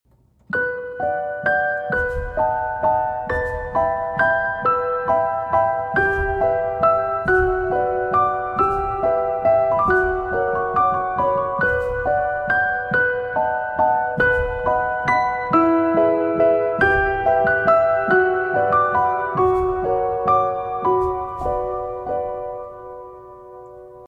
Gemstone Ice ASMR | PT1 sound effects free download